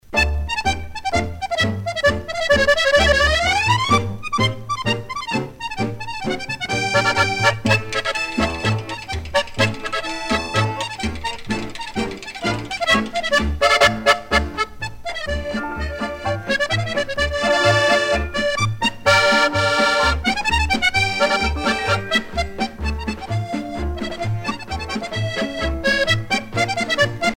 paso musette
Pièce musicale éditée